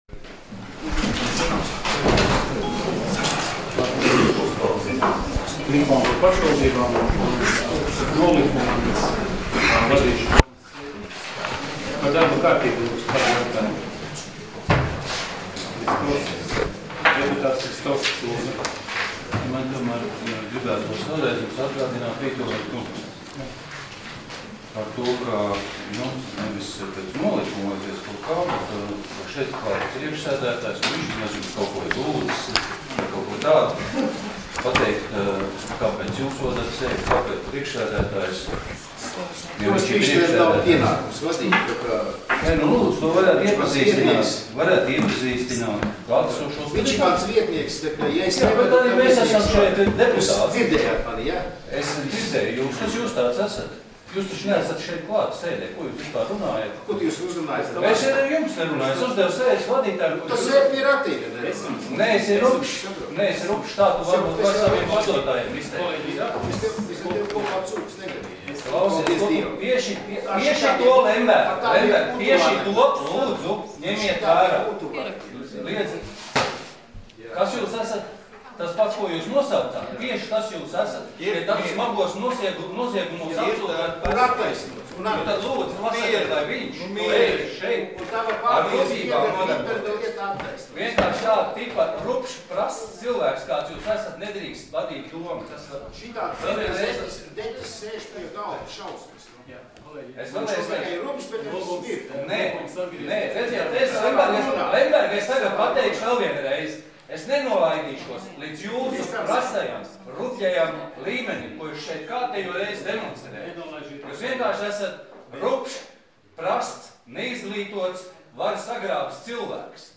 Domes sēdes 16.02.2018. audioieraksts